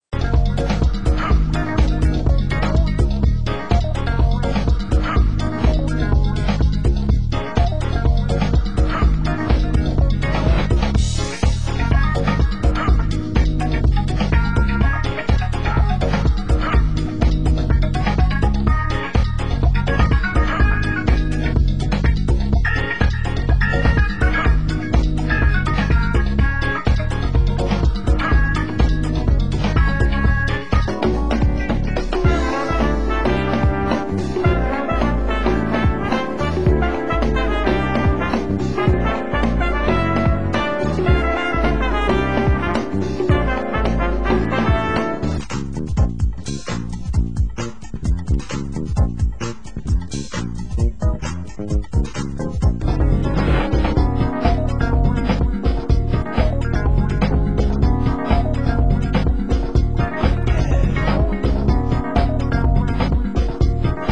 Genre: Future Jazz